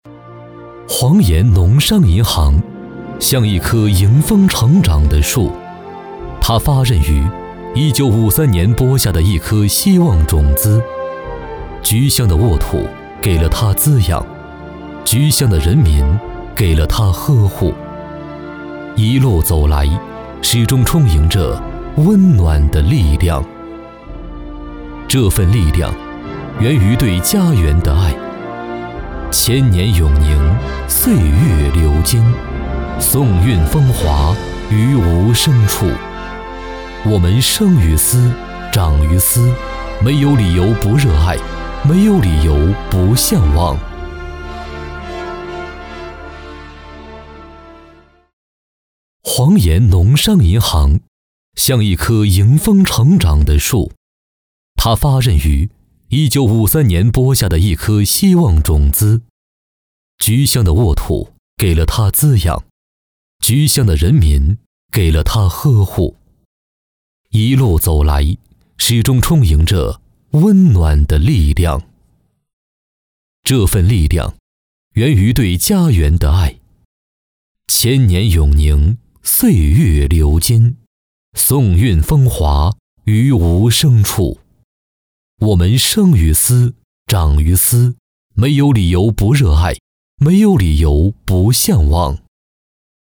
男52号配音师
专题片-男52-厚重情感讲述 农商银行形象片.mp3